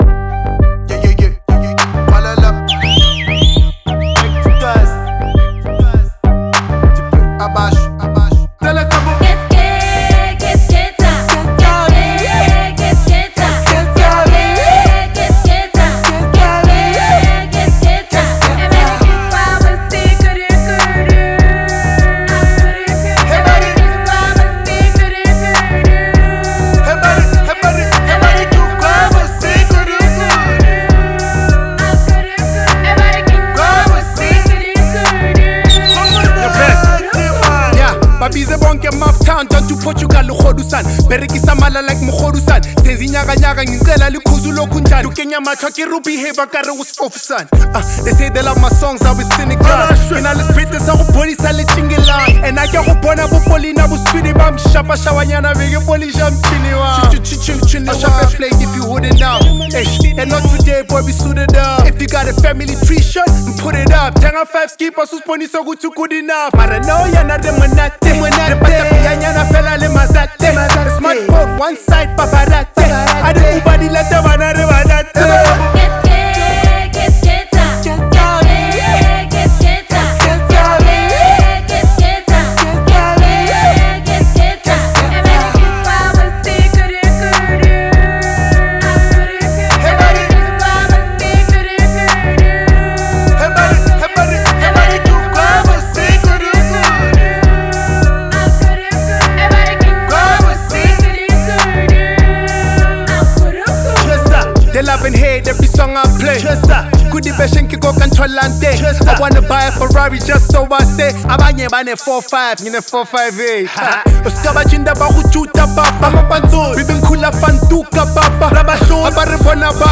Legendary SA rapper